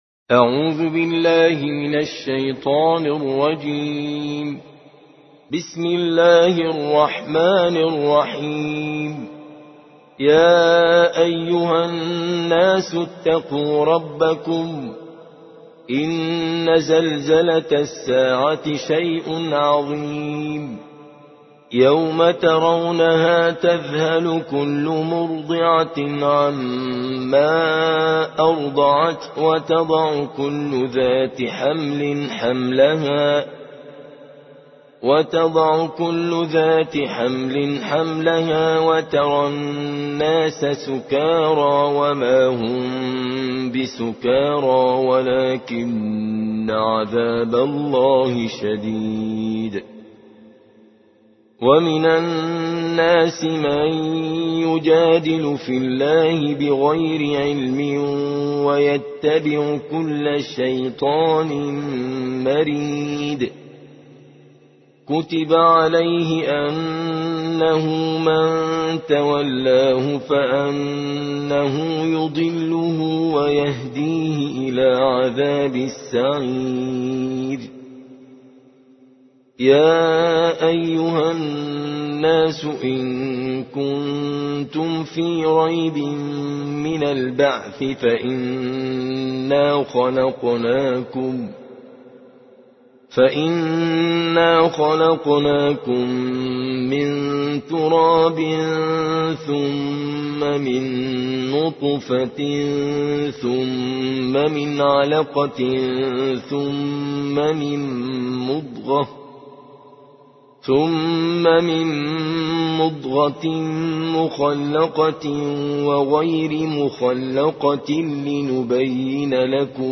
22. سورة الحج / القارئ